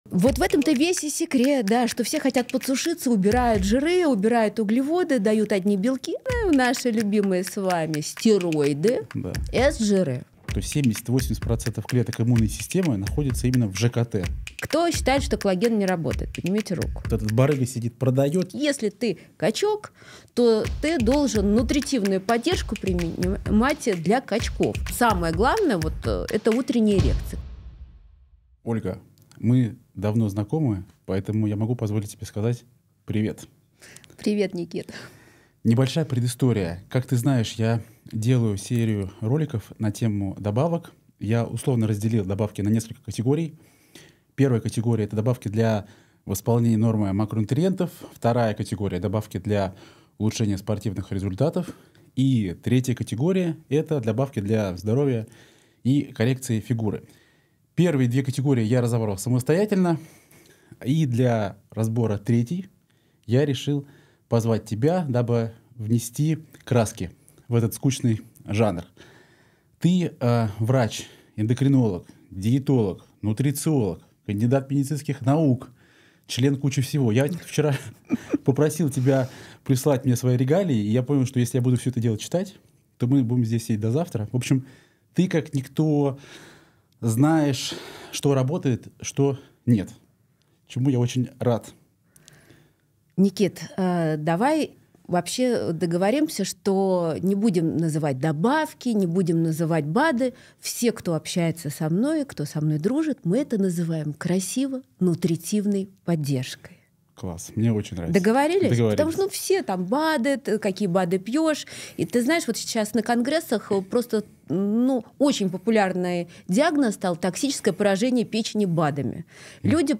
Источник: Персональное интервью